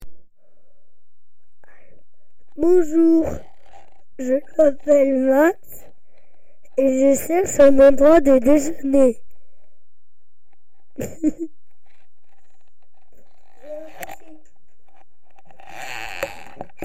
Témoignage enregistré le 15 octobre 2025 à 9h44